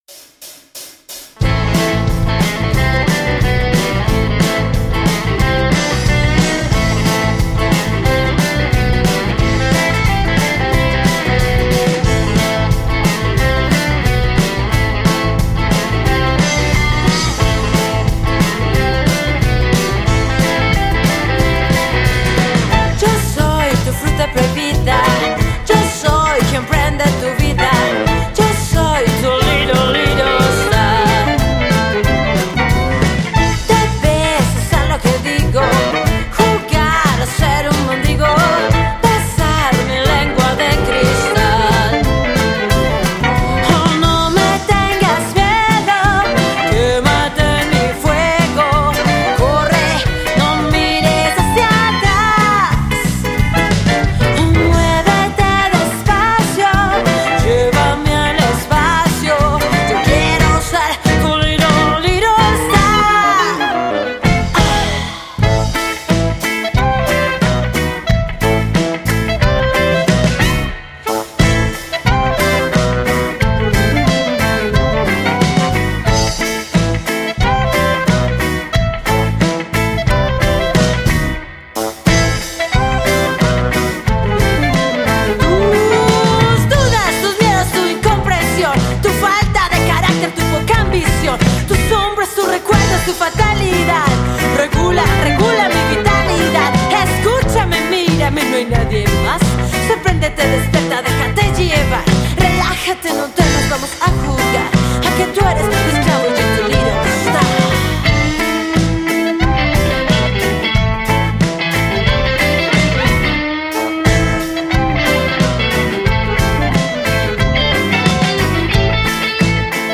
En la batería esta el ritmo y el swing
el bajo camina, movimiento sin fin.
Suena la guitarra hermosos acordes
el saxo desgarra gran brillo de bronces.
Y en el clarinete sonido gitano
Jazz rock